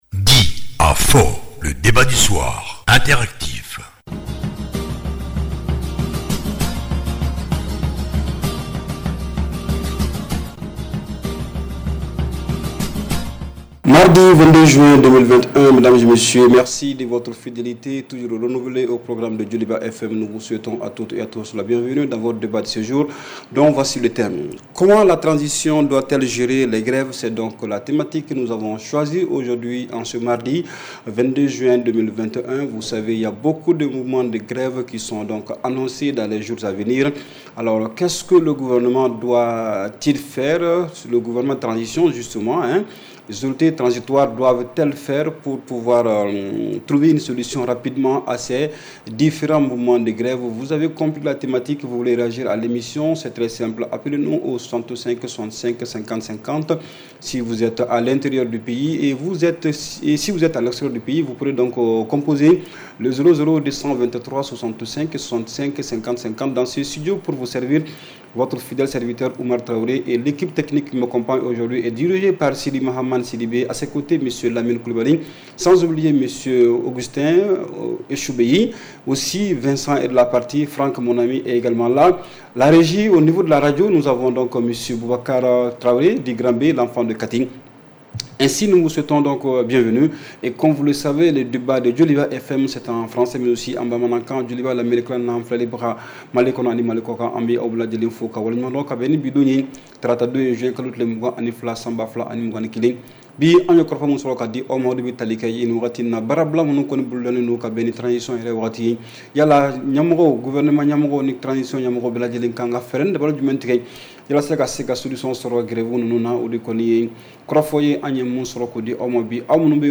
REPLAY 22/06 – « DIS ! » Le Débat Interactif du Soir